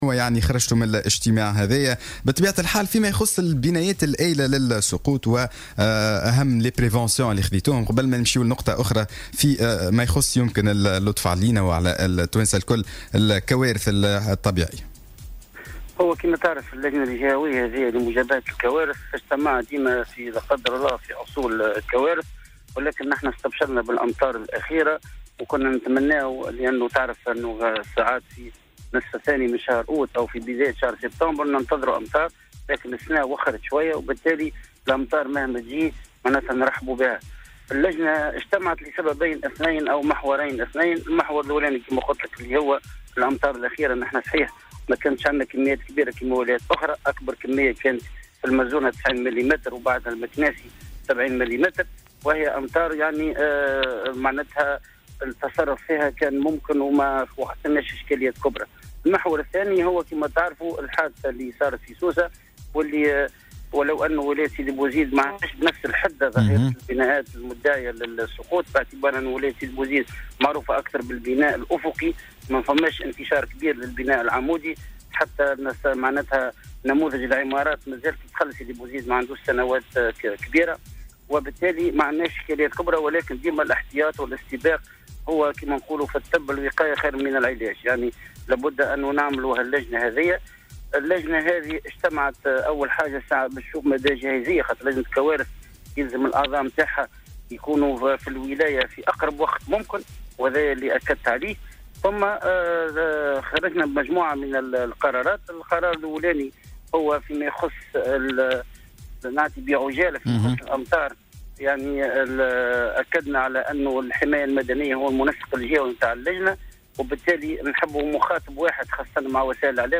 أكد والي سيدي بوزيد مراد محجوبي في مداخلة له في صباح الورد اليوم الثلاثاء أن اللجنة الجهوية لمجابهة الكوارث انعقدت موفى الأسبوع الماضي وخرجت بمجموعة من القرارات في ما يتعلق بالأمطار المنتظرة والبنايات المتداعية للسقوط الموجودة بالجهة.